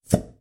Звуки присоски